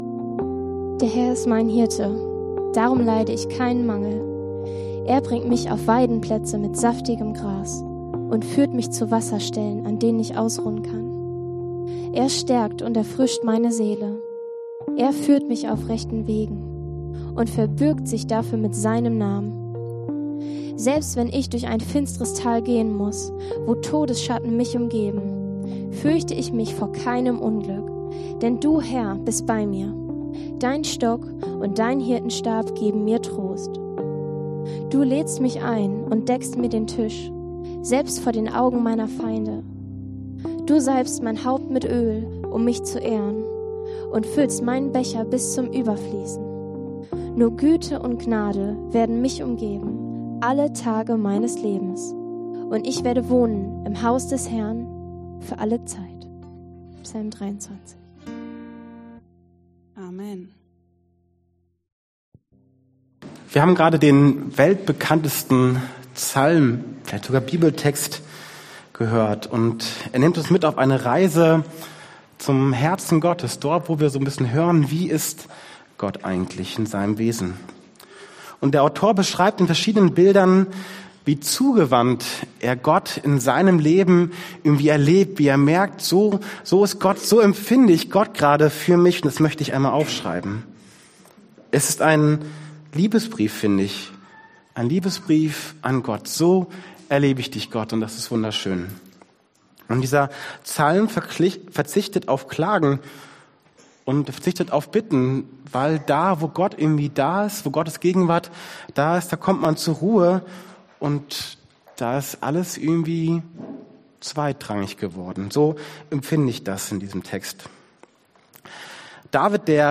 Die Psalmen Passage: Psalm 23; Mt 11,28-29; Hebr 4,1-11 Dienstart: Predigt Themen